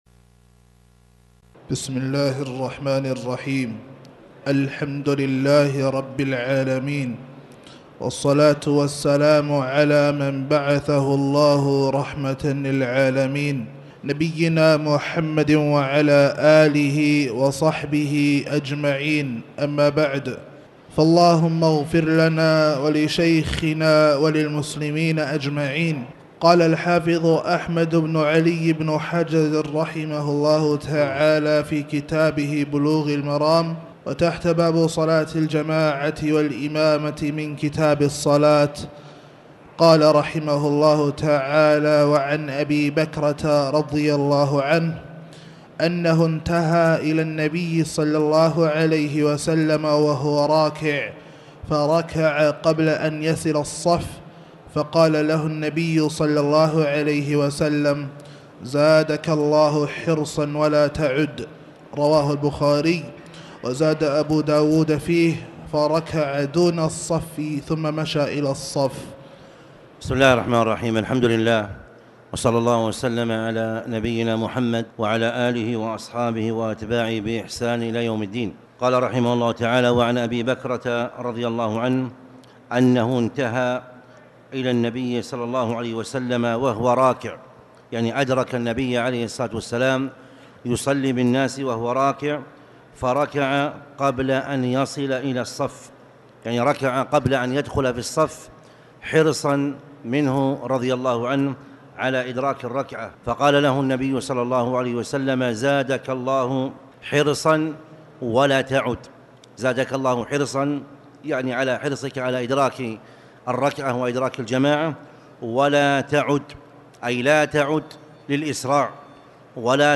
تاريخ النشر ٢٧ صفر ١٤٣٩ هـ المكان: المسجد الحرام الشيخ